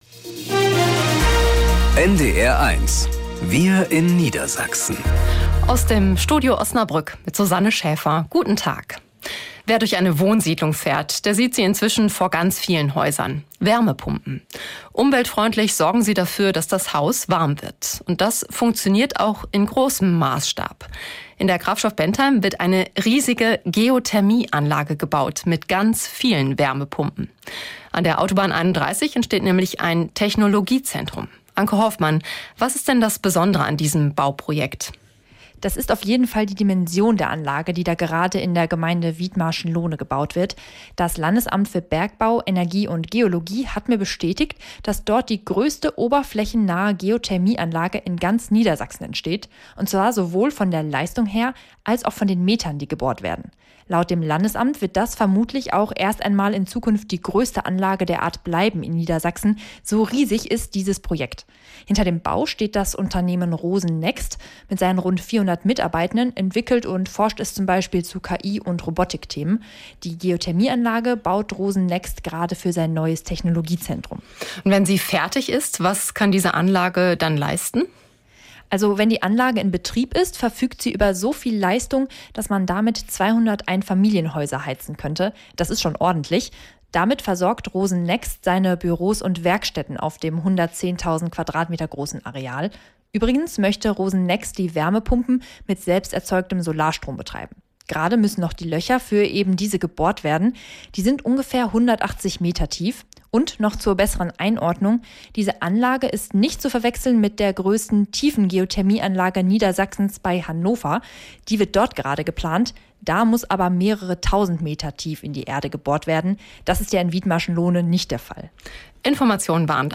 Wir in Niedersachsen - aus dem Studio Osnabrück | Nachrichten